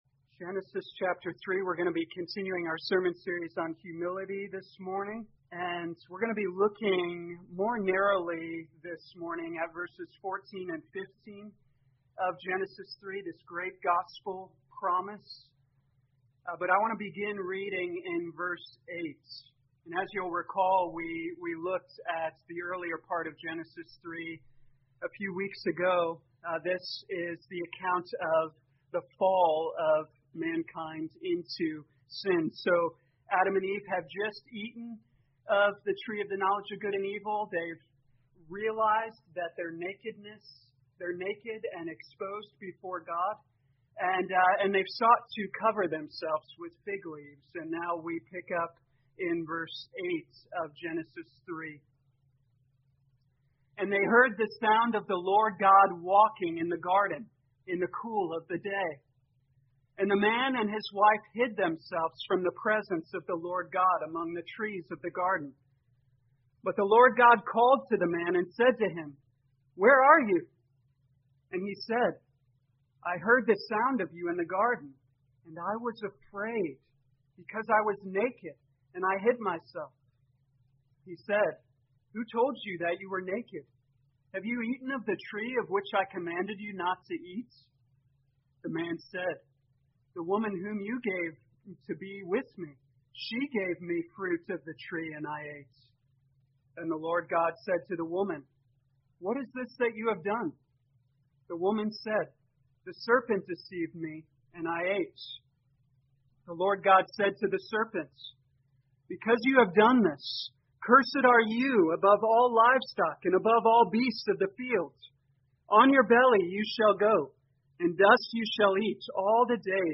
2020 Genesis Humility Morning Service Download